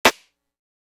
11 snare hit.wav